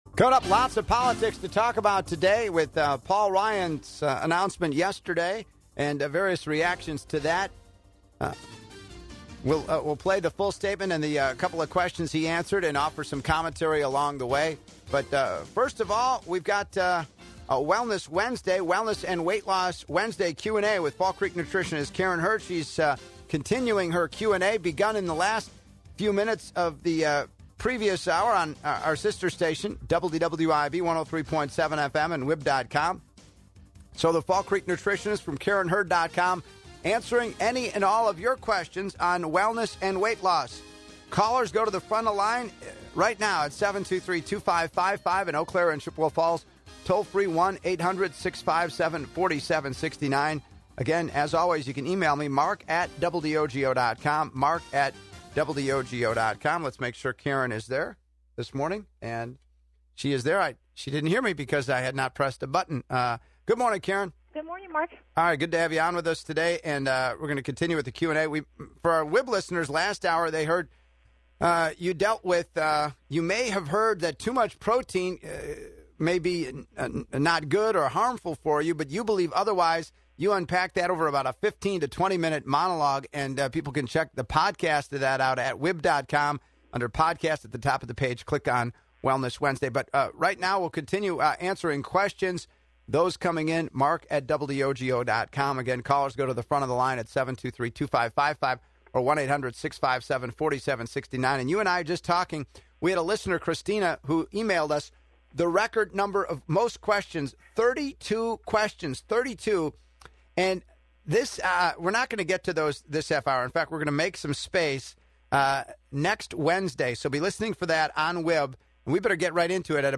answers questions; House Speaker Paul D. Ryan tries clarifying more than ever he will NOT accept his party's nomination for President; Sen. Ted Cruz(R-TX) says he's not concerned about the GOP establishment stealing the nomination from him.